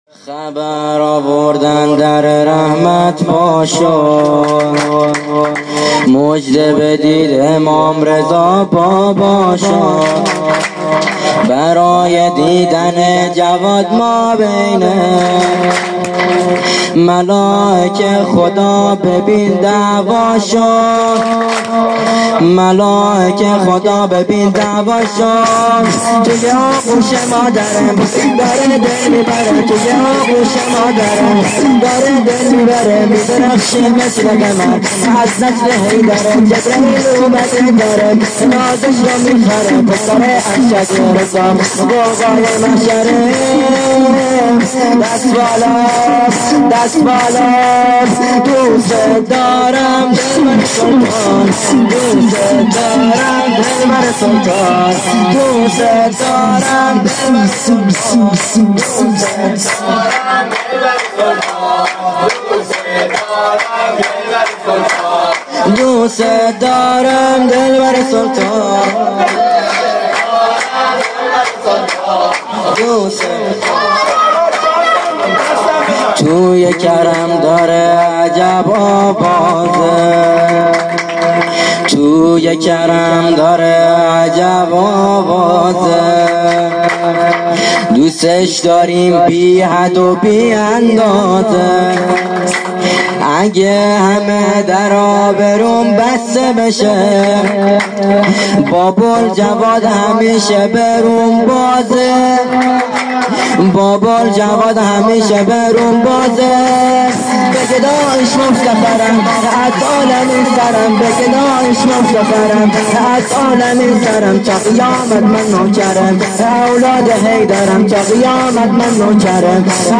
جشن ها